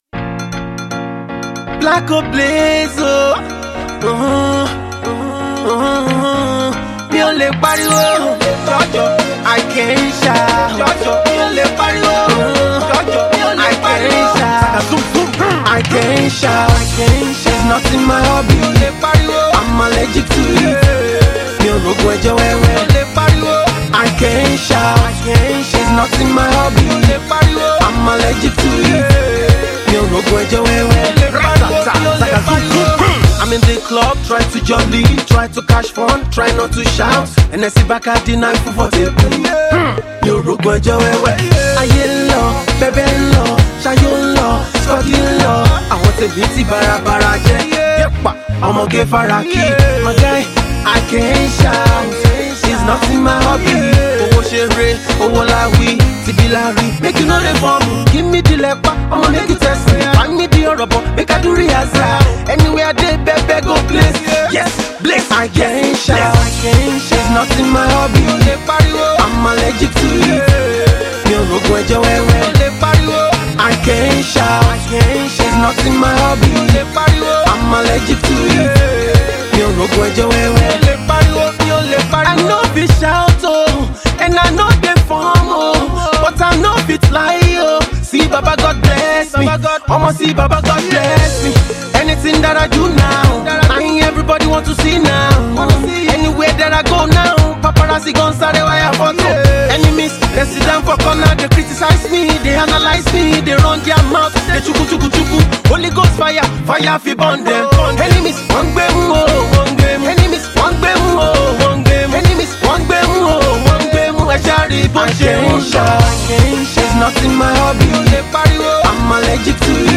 his crooning